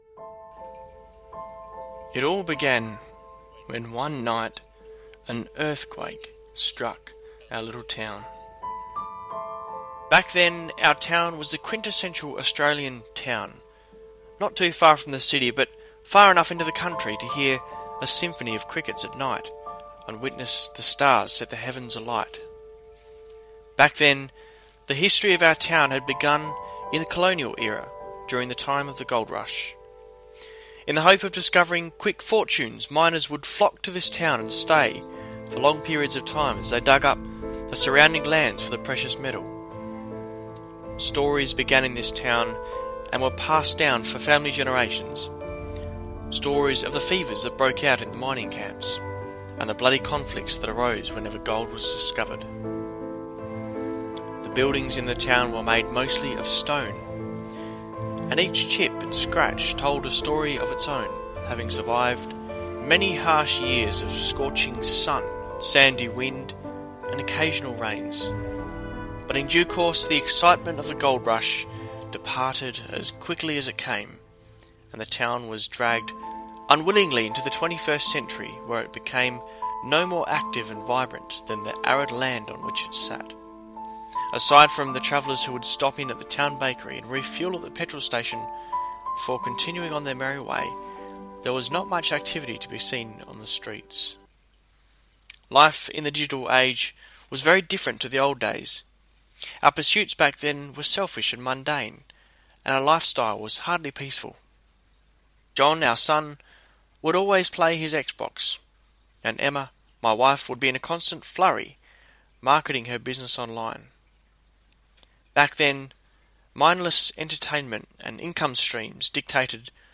This series was given for a Wednesday night class and it was requested that it be made available to be shared around.